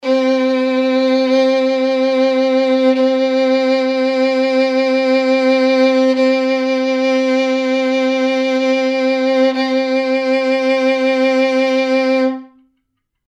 interactive-fretboard / samples / violin / C4.mp3
C4.mp3